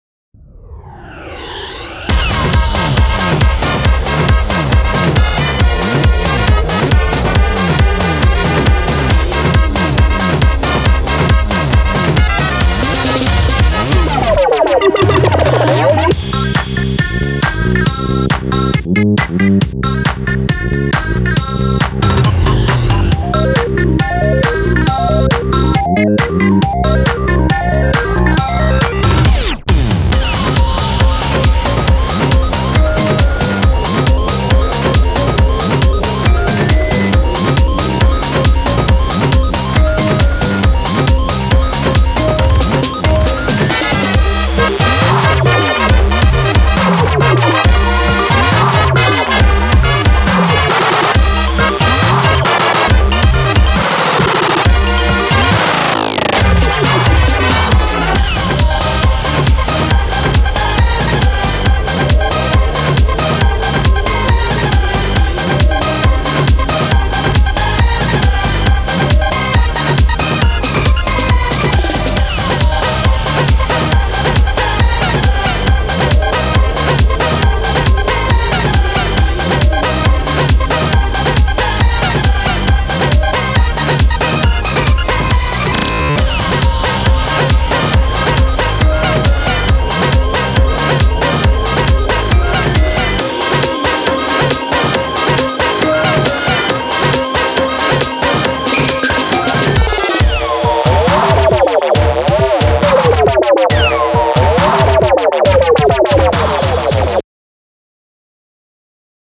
BPM137
Audio QualityPerfect (High Quality)
The song is quite... funky for some reason.